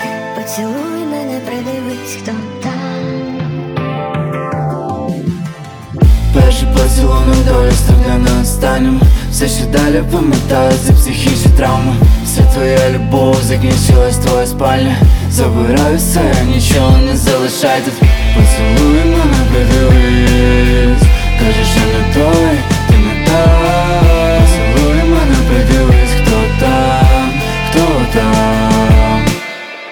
Жанр: Рок / Украинские